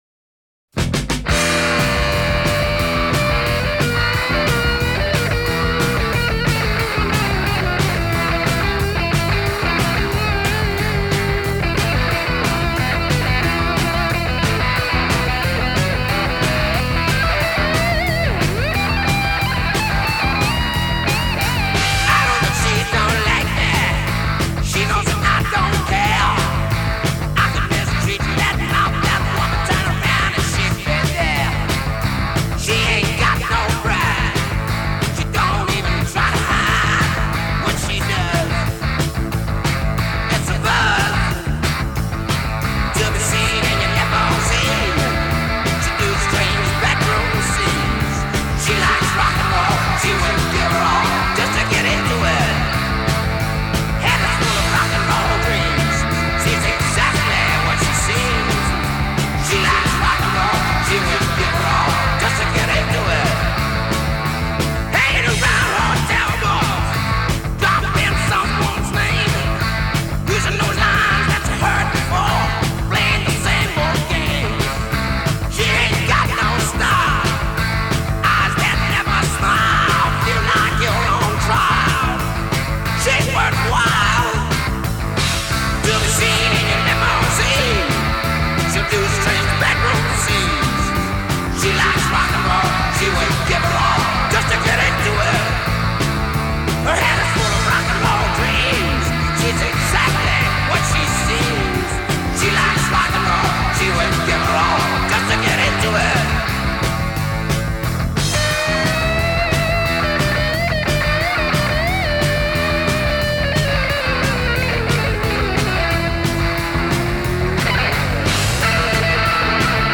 Genre: Rock
Style: Hard Rock